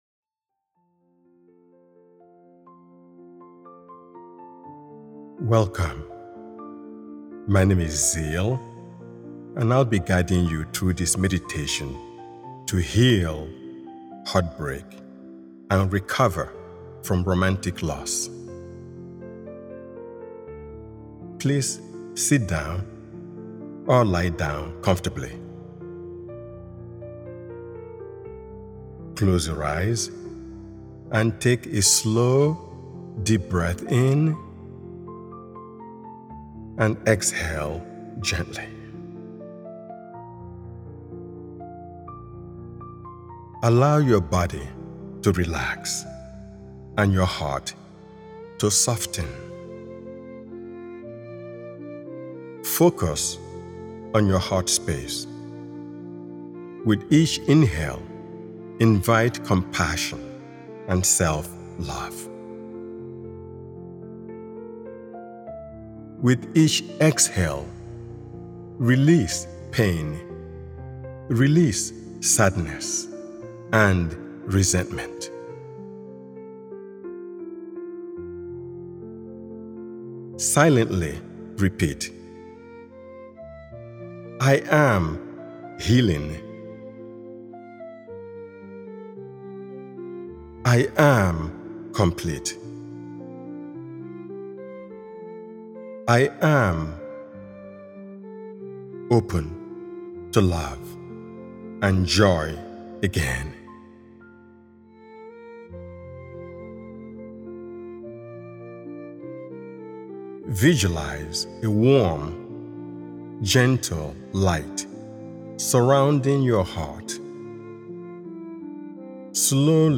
This extended meditation experience is designed to wrap you in calm, offer emotional companionship, and guide you toward a renewed sense of inner peace.